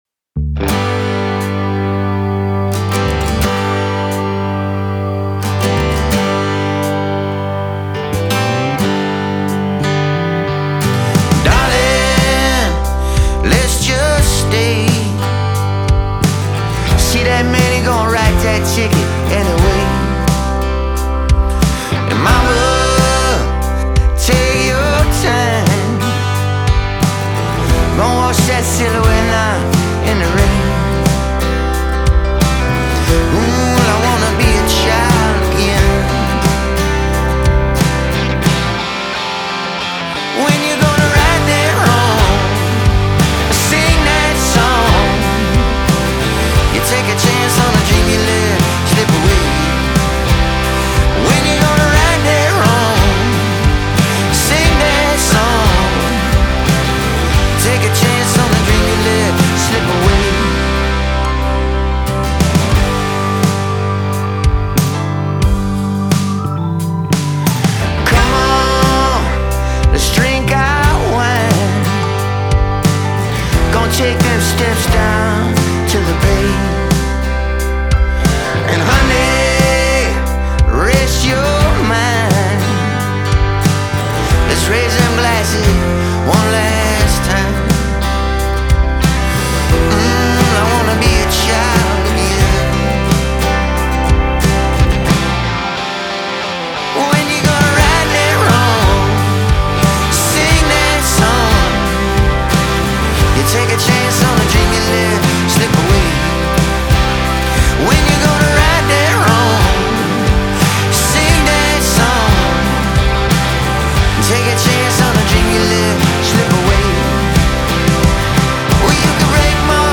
Mal angemischt